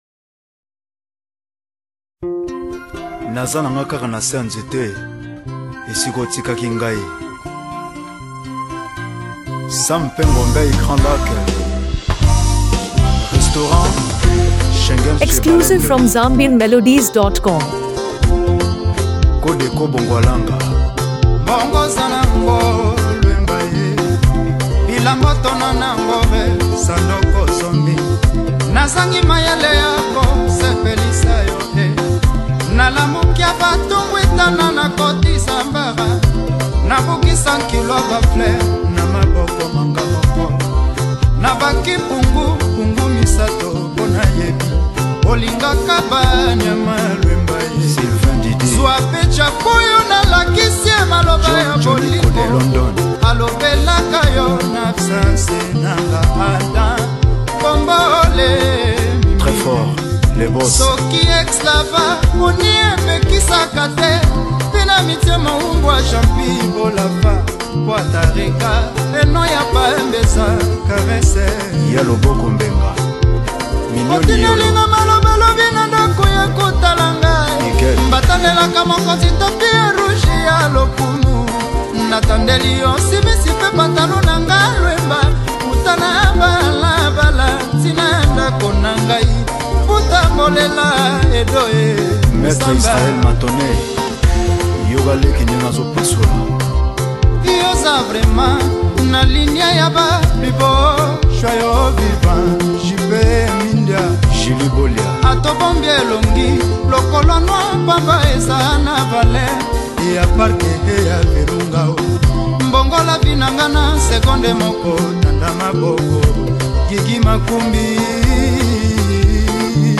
A Powerful Blend of Rhythm and Emotion
is both melodically soothing and lyrically intense.